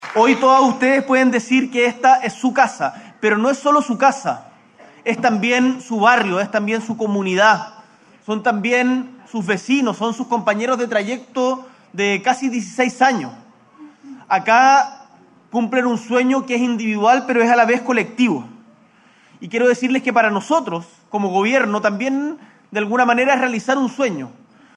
“Hoy inicia una nueva etapa para las familias después de muchísima espera y lucha. Algunos incluso pensaron que este día no iba a llegar, pero al final del día, cuando hacemos buena política pública, avanzamos”, destacó el jefe de Estado en su intervención.